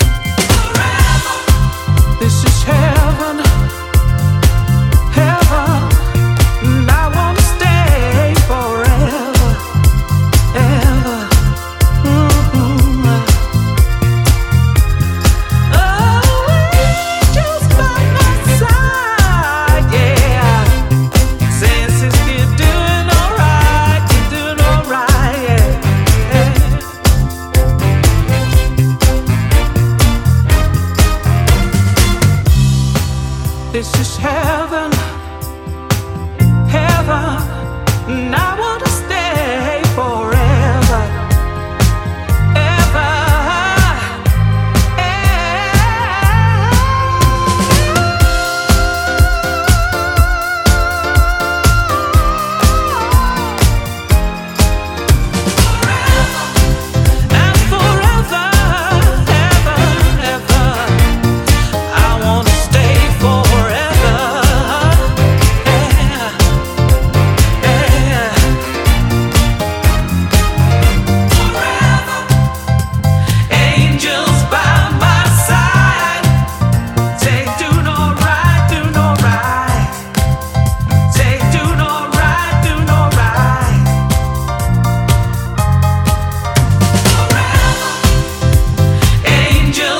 ジャンル(スタイル) DISCO / NU DISCO / SOULFUL HOUSE